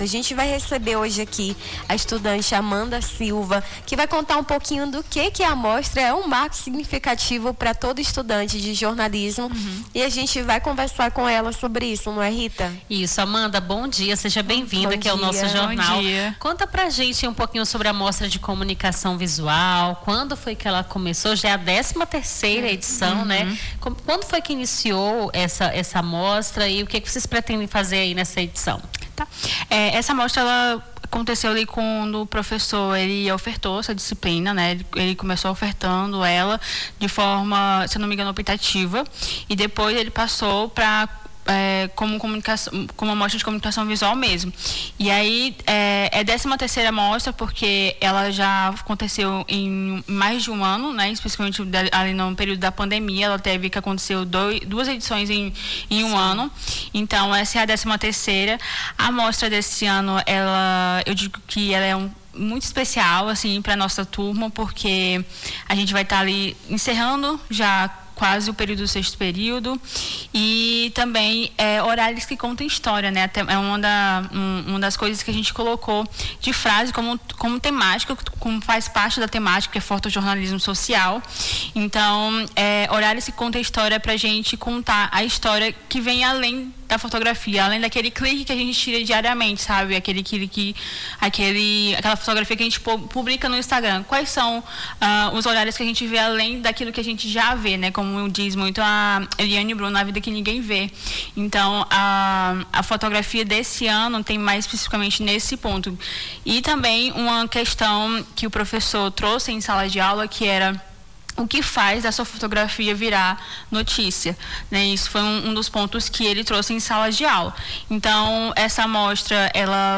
Nome do Artista - CENSURA - ENTREVISTA (13 MOSTRA DE COMUNICAÇÃO VISUAL) 08-10-25.mp3